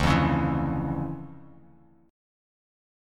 DmM9 Chord
Listen to DmM9 strummed